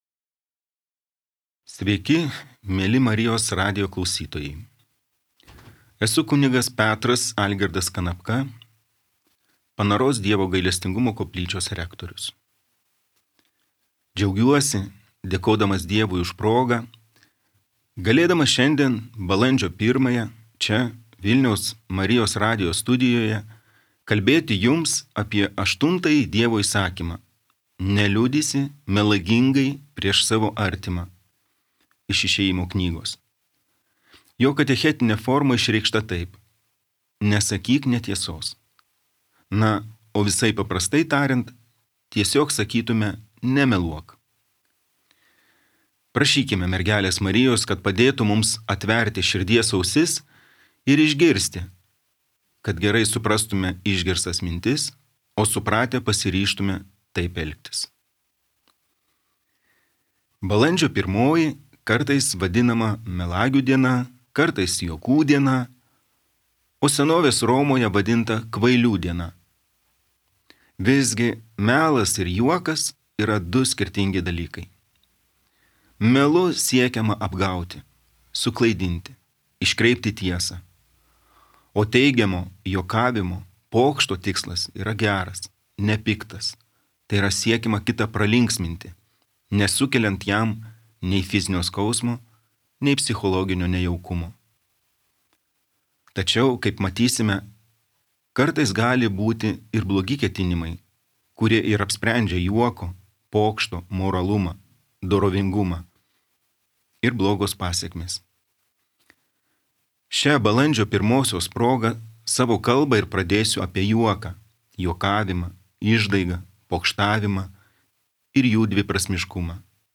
Écoutez Katechezė.